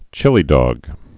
(chĭlē-dôg, -dŏg)